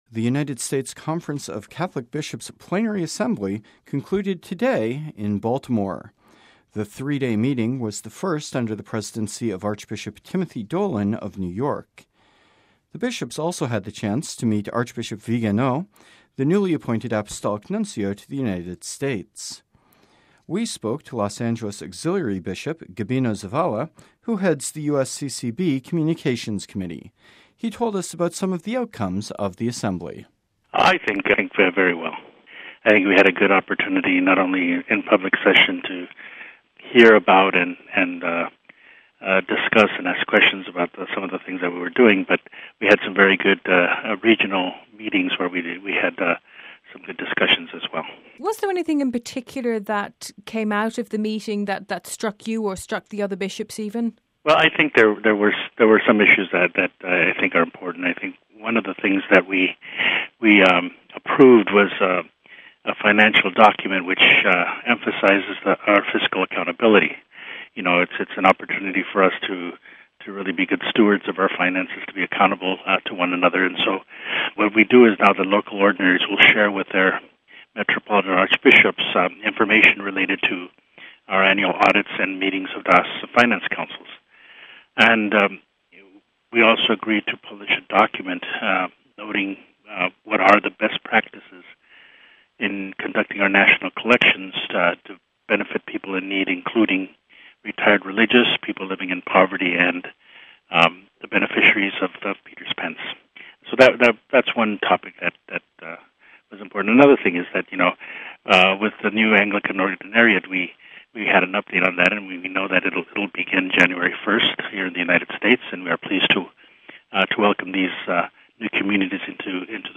“I think we had a good opportunity not only in public session to hear about and discuss and ask questions about some of the things that we were doing”, says Los Angelus Auxiliary Bishop Gabino Zavala, who heads the USCCB communications committee.